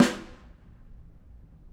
Snare2-HitSN_v3_rr1_Sum.wav